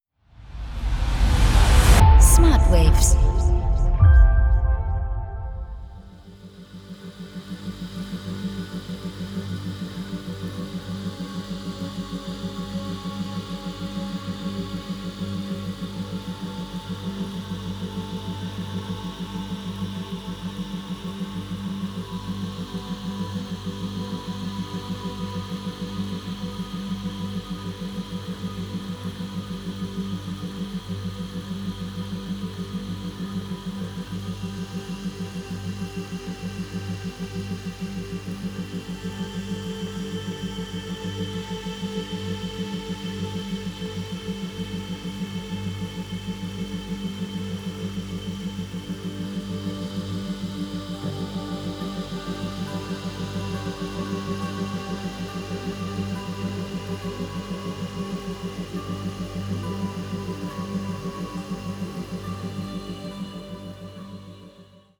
4-8 Hertz Theta Wellen Frequenzen